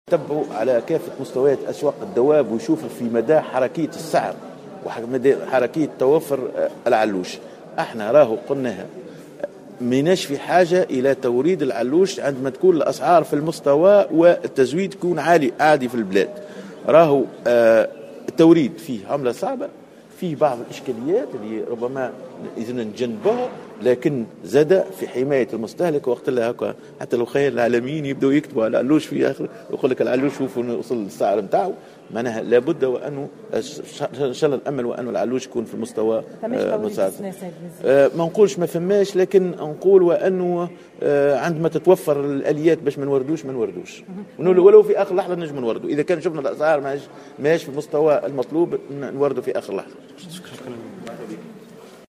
وأكد الوزير في تصريح إعلامي على هامش إشرافه صباح اليوم الخميس على هامش حضوره في مجلس نواب الشعب للنظر في مشروع قانون يتعلق بالمنافسة والأسعار، إن الوزارة قد تلجأ في آخر لحظة إلى التوريد في حال لاحظت ارتفاعا مفاجئا في الأسعار.